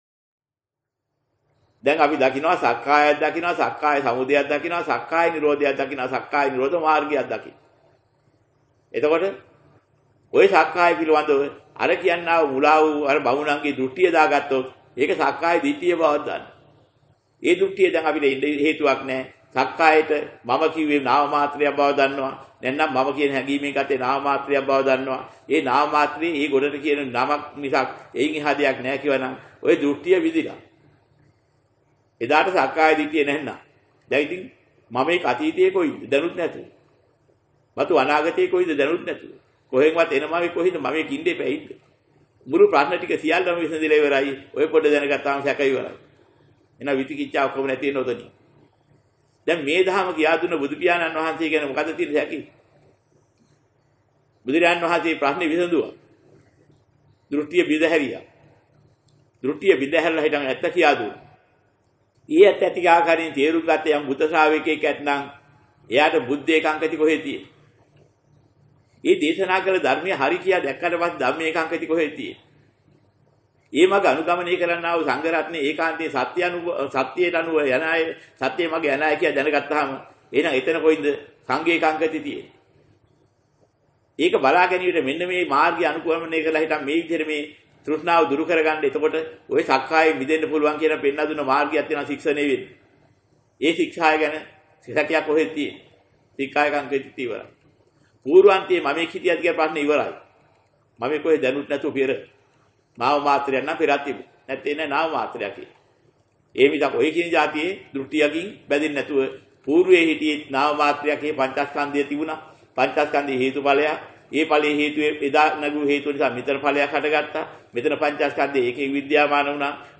මුලික දේශනා: තථාගත දේශනාවට අනුවම අනත්ථ තෝරා ගනිමු...